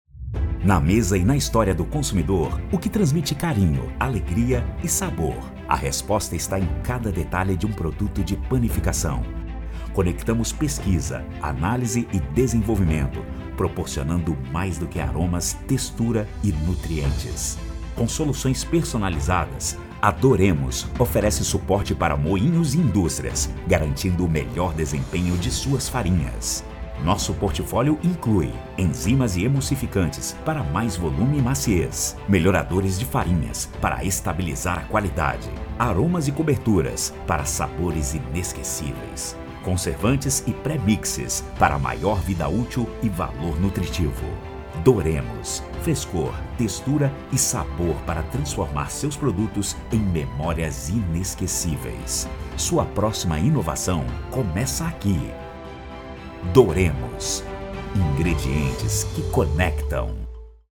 Voz profissional, qualidade de áudio e rapidez na entrega.